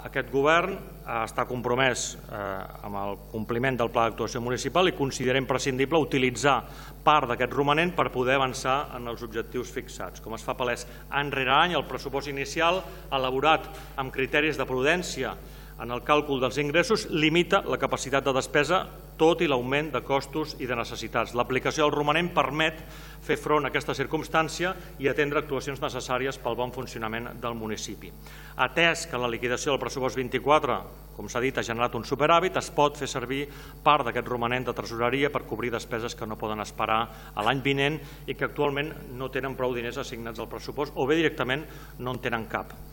El ple d’aquest dimarts 1 de juliol ha aprovat el destí del superàvit del 2024.
alcalde-compliment-PAM.mp3